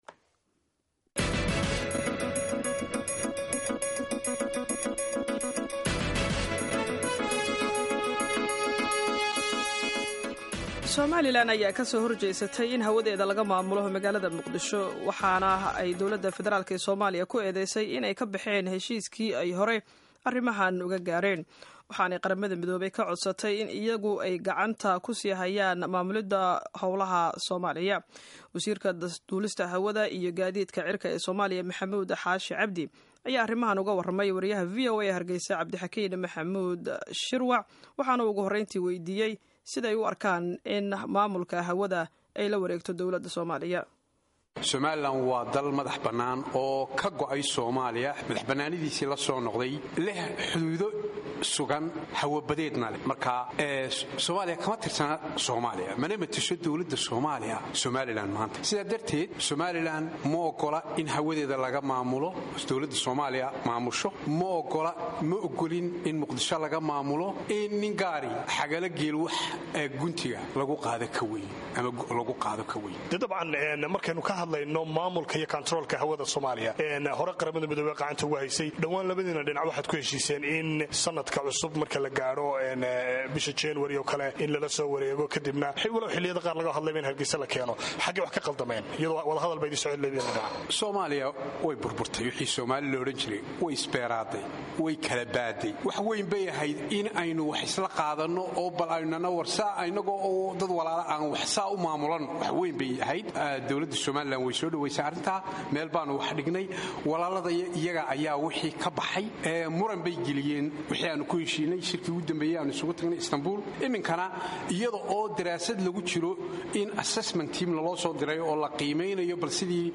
Dhageyso wareysiga Wasiirka Duulista Somaliland